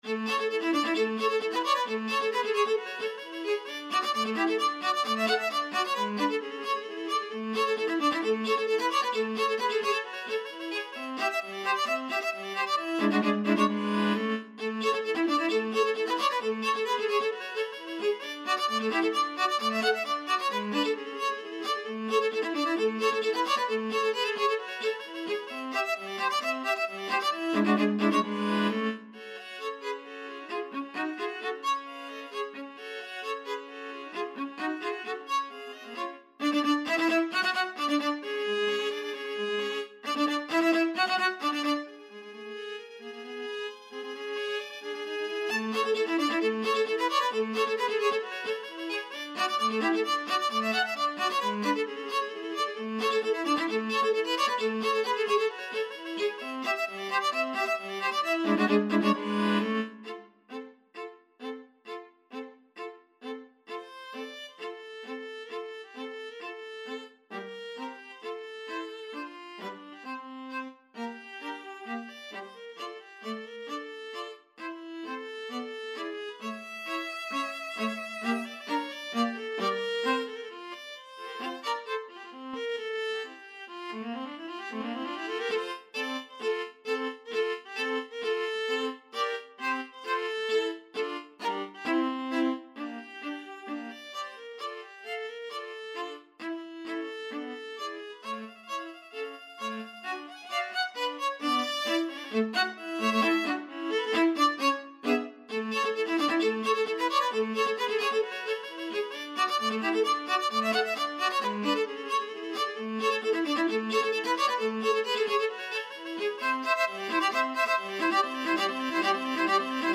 2/4 (View more 2/4 Music)
Viola Trio  (View more Intermediate Viola Trio Music)
Classical (View more Classical Viola Trio Music)